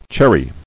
[cher·ry]